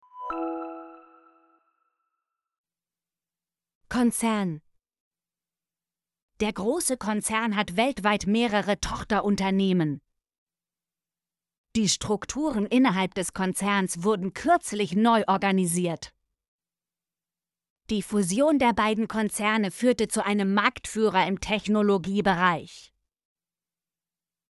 konzern - Example Sentences & Pronunciation, German Frequency List